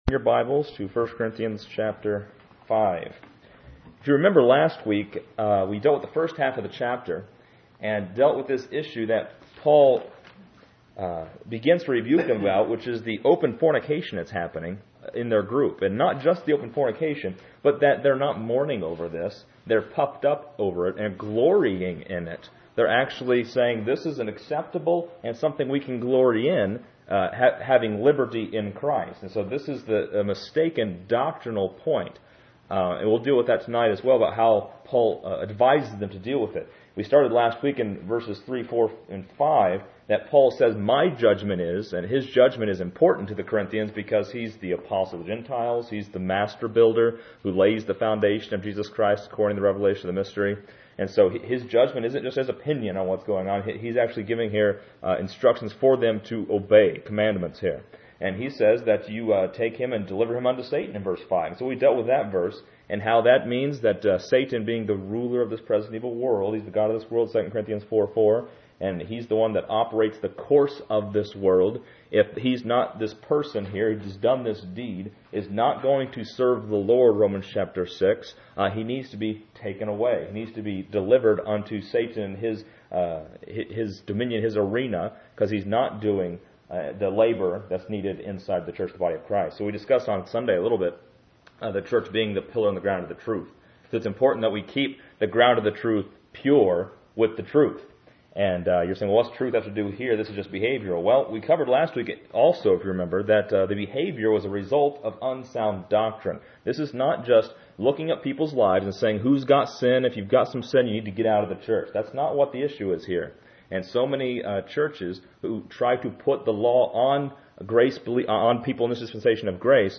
This lesson is part 14 in a verse by verse study through 1 Corinthians titled: Purging the Leaven.